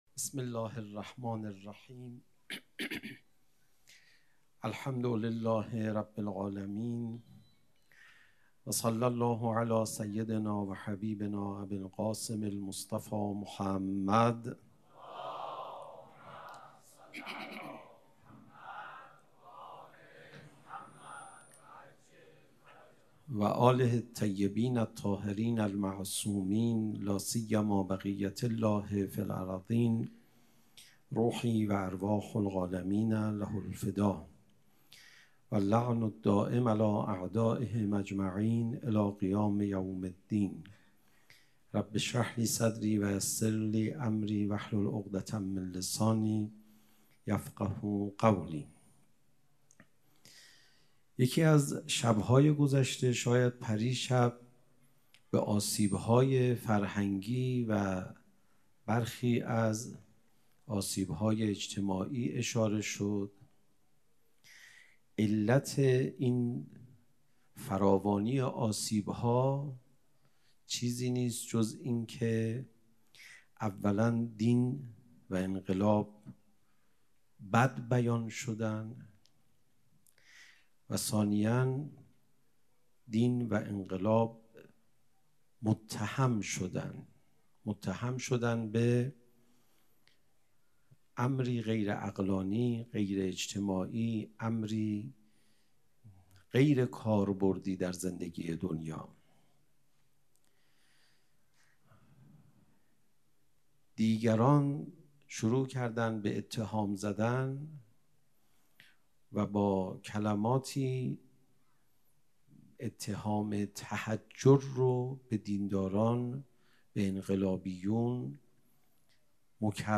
سخنرانی: سخنرانی حجت‌الاسلام والمسلمین علیرضا پناهیان